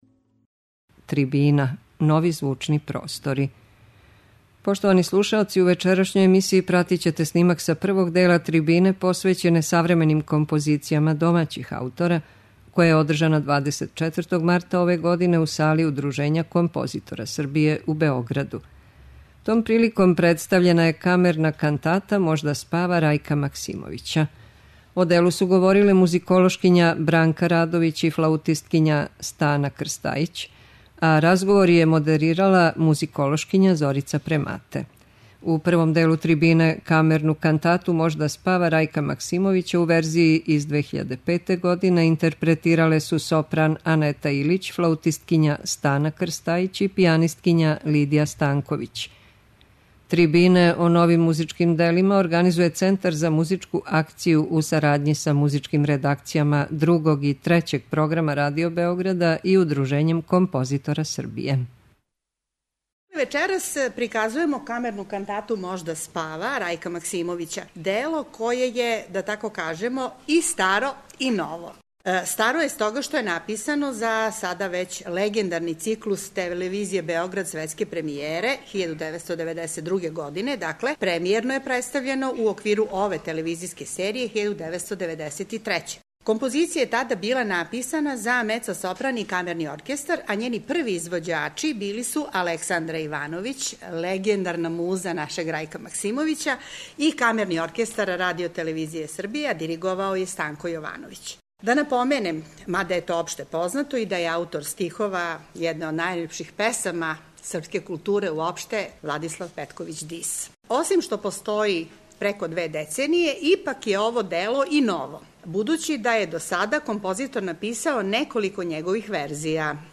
Слушаћете снимак са првог дела трибине посвећене савременим композицијама домаћих аутора, која је одржана 24. марта ове године у Сали Удружења композитора Србије у Београду. Том приликом представљена је камерна кантата Можда спава Рајка Максимовића.